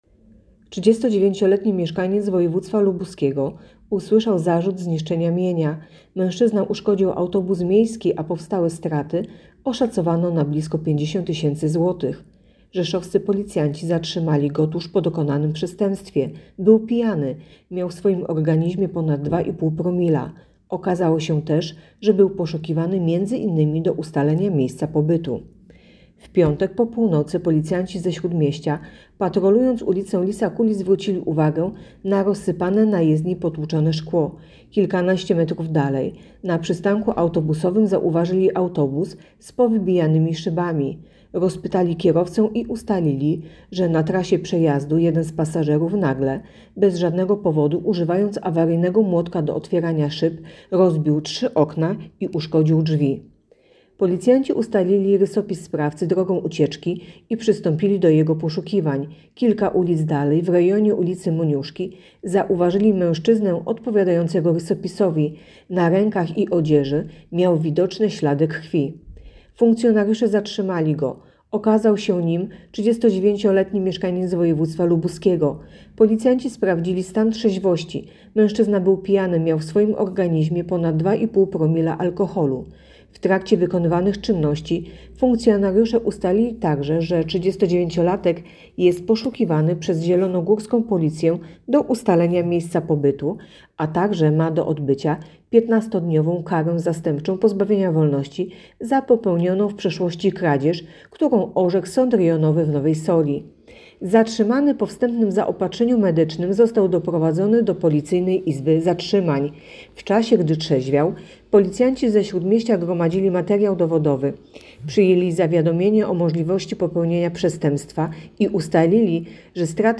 Mówi podkomisarz